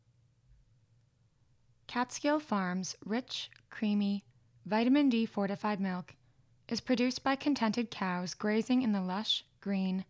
Keeping in mind my room is untreated right now and I usually record at night to avoid cars/ other noises, there may also be some background noise picked up. I didn’t edit the test sample at all.
I did turn up the gain until the light started flashing green as I spoke, so that’s the setting I recorded on just now.
I did hear low pitch noises such as coming from vents, air conditioners, heaters, and computer fans.